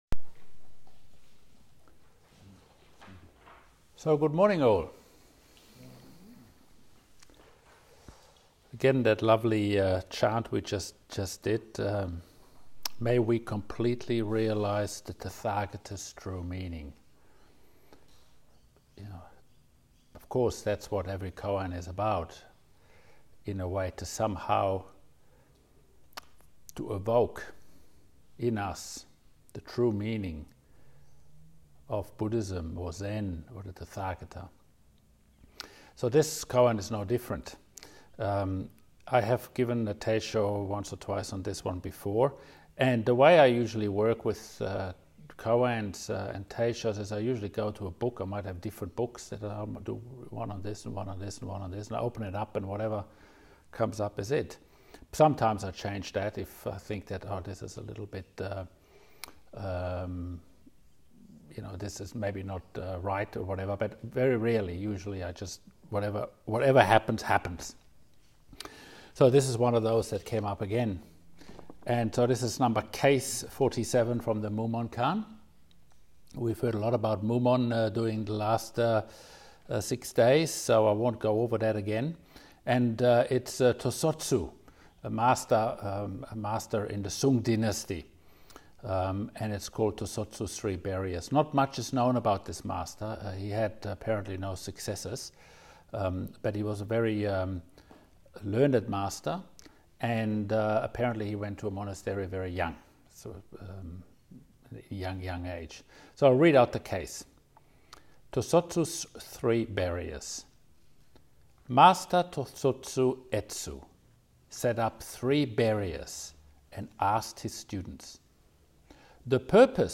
Teisho
at the 2021 Pathway Zen Sesshin at Highfields, QLD, Australia.